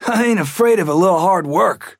Trapper voice line - I ain't afraid of a little hard work!